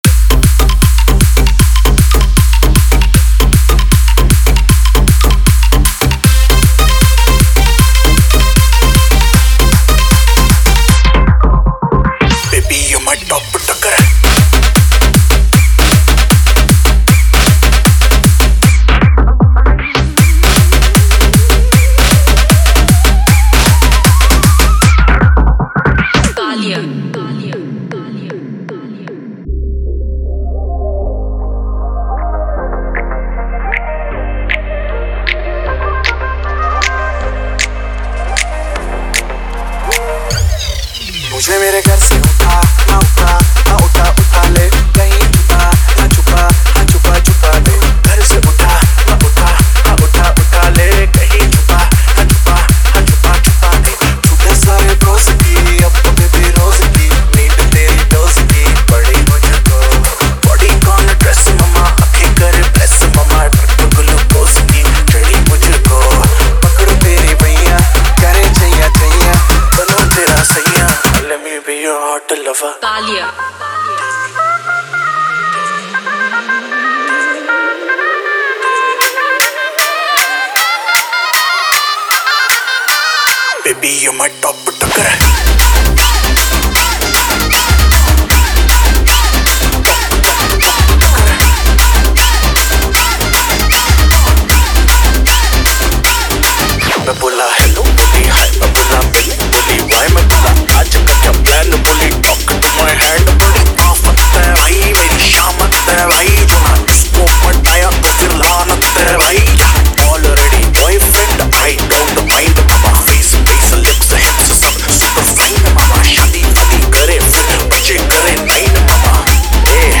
Trance South Mix